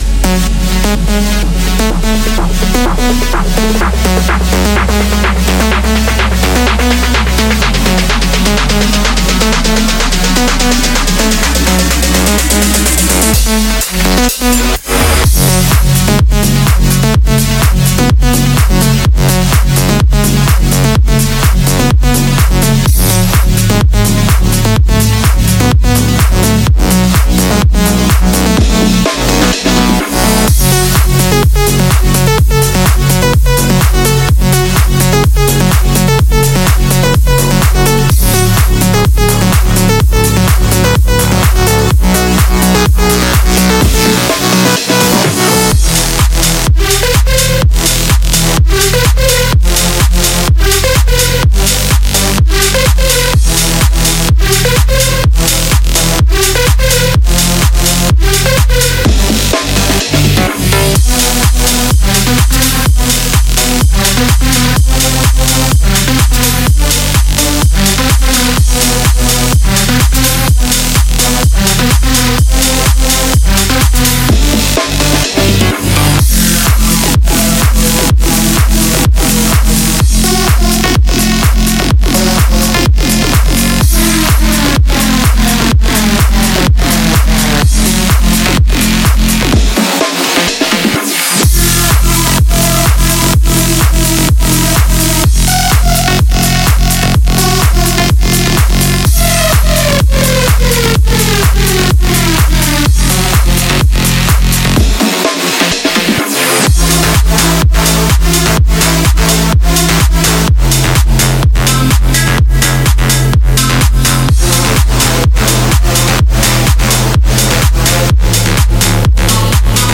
音频演示中的所有声音都包含在此收藏集中（不包括鼓和效果），仅占它们处理能力的一小部分。
30 bass presets
42 lead presets
31 pluck presets